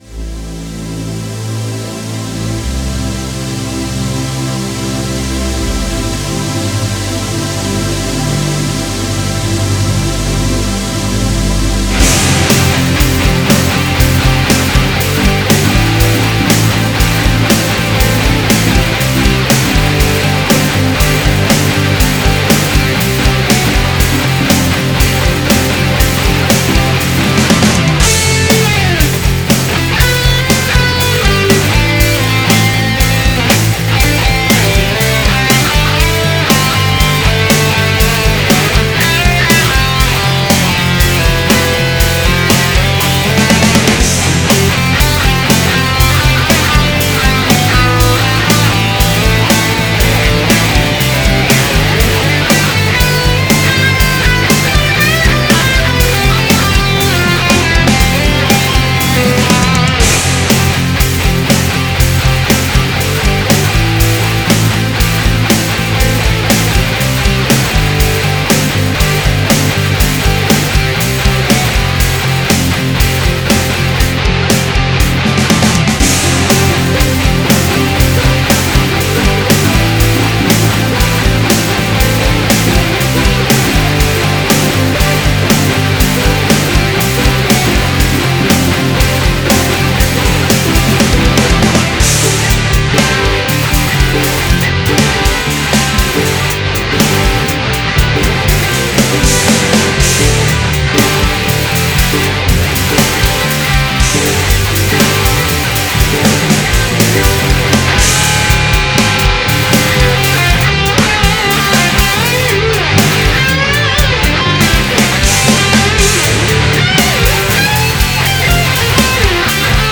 (strumentale)